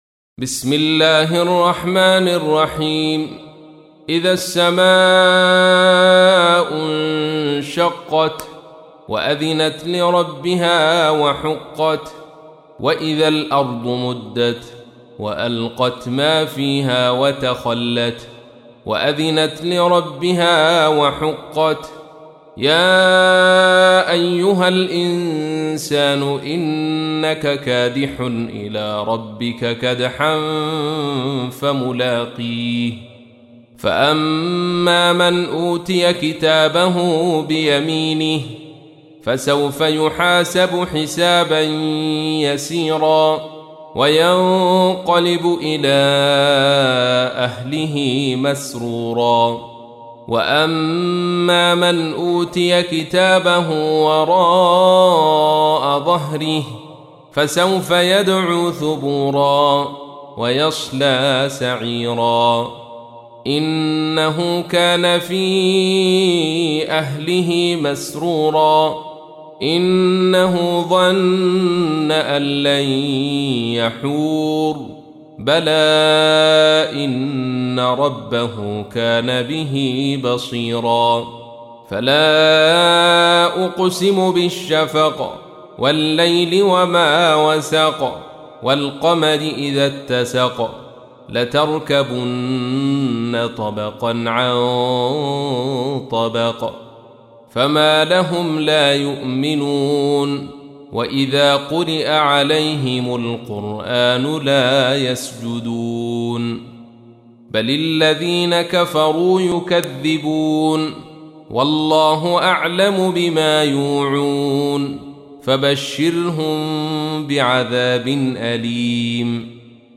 تحميل : 84. سورة الانشقاق / القارئ عبد الرشيد صوفي / القرآن الكريم / موقع يا حسين